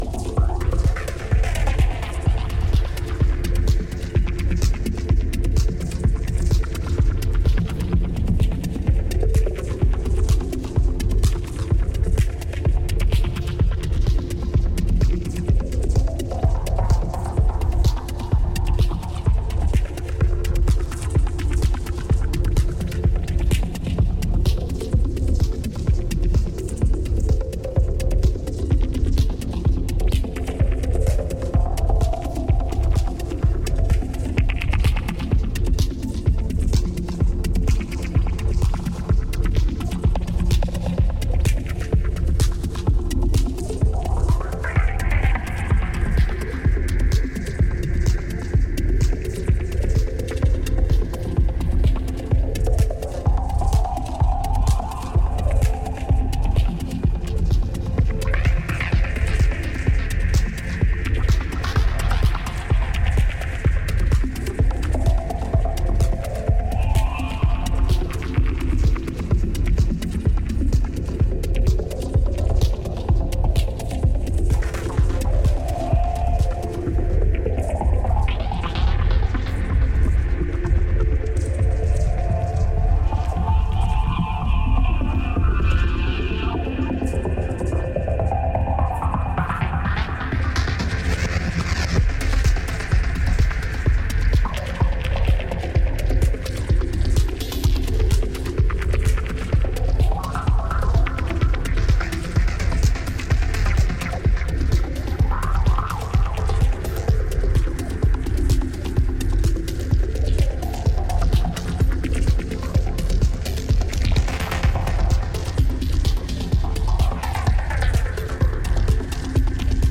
New Release Dub Techno Techno